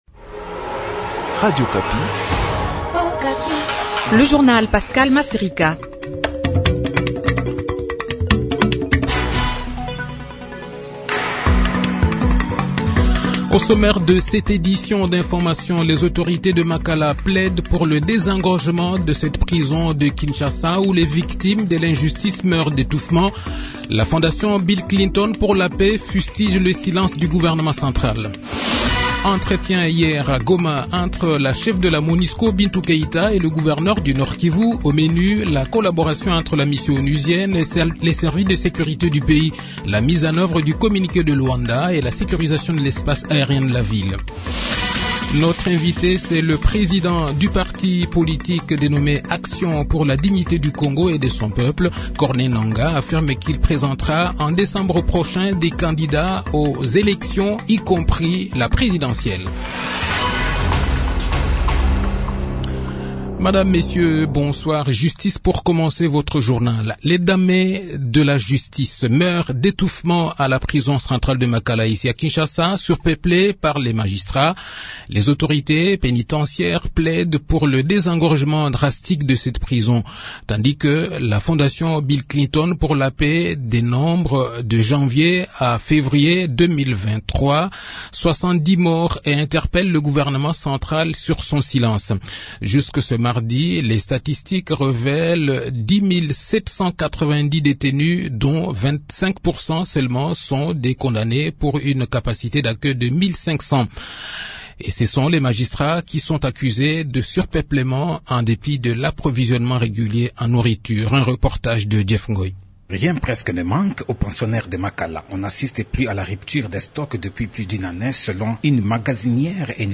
Journal Soir
Le journal de 18 h, 28 février 2023